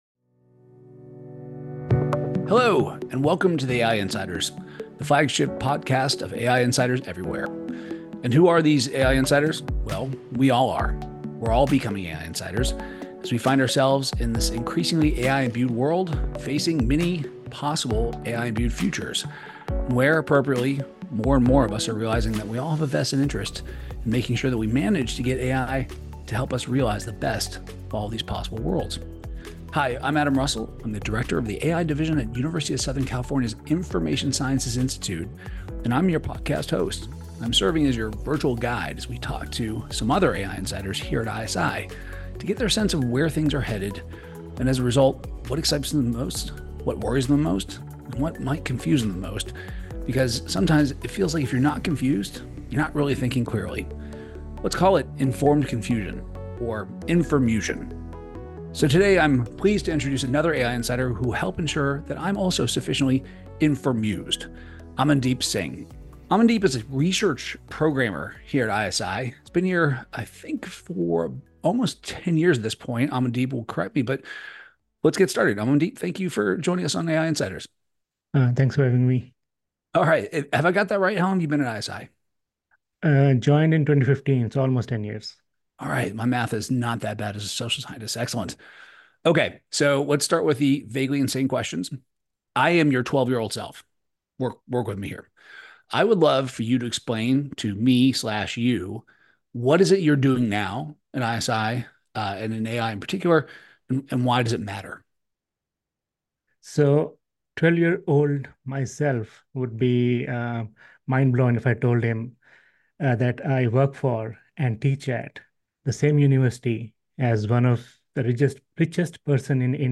interviews the humans behind AI to understand what motivates them, how they shape AI today, and where they hope AI will go.